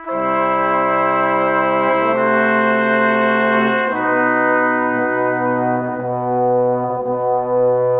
Cinematic 27 Horns 03.wav